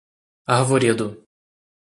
Pronúnciase como (IPA)
/aʁ.voˈɾe.du/